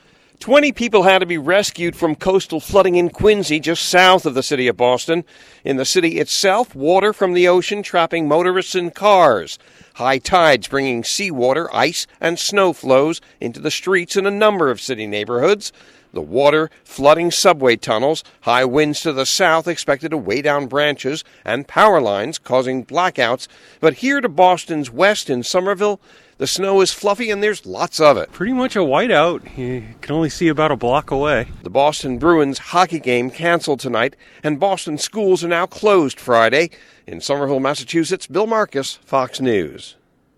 WENT OUT AND SPOKE TO A FEW.